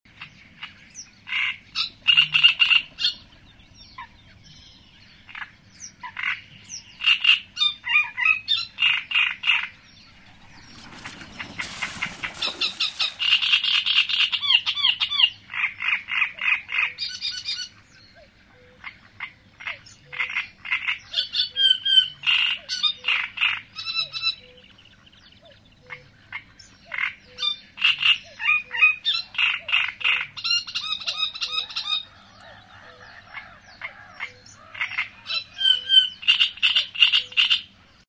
A nádasokban élő madaraink ritkán kerülnek a szemünk elé, de hangjuk semmivel össze nem téveszthető hangulatot áraszt. A nádasokban él a jellegzetes énekű (kara-kara-ki-ki-ki) nádirigó (Acrocephalus arundinaceus)